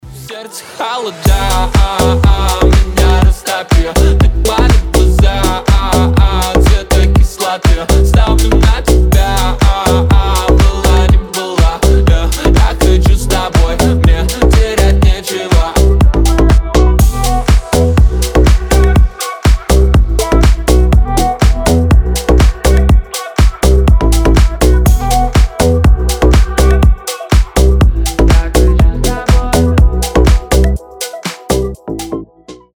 танцевальные , заводные , ритмичные